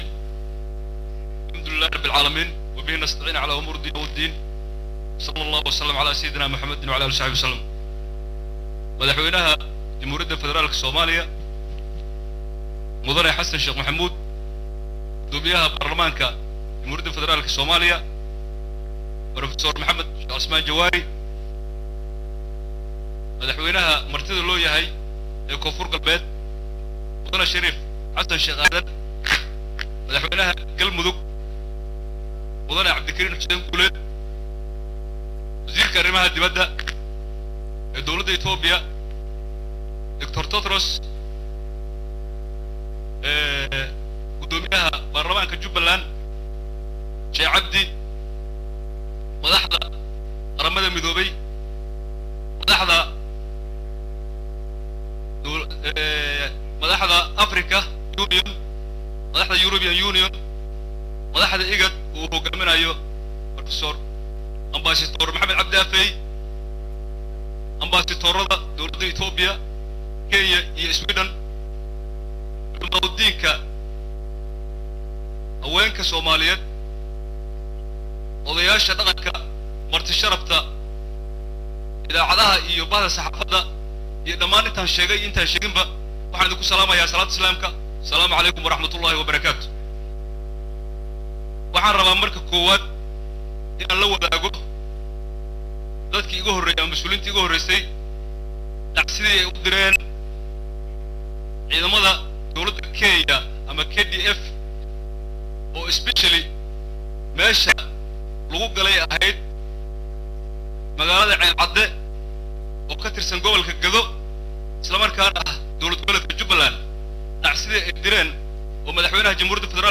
Dhageyso: Khudadii Madaxweyne Ku Xigeenka Maamulka Jubba Uu Ka Jeediyey Caleemo Saarka Gudoonka BKGS
Baydhabo(INO)-Madaxweynaha Xigeenka Jubba State General C/laahi Ismaaciil fartaag ayaa isna maanta Khudbad ka jeediyey Caleemo Saarka Gudoonka Baarlamanka Koonfur Galbeed Soomaaliya.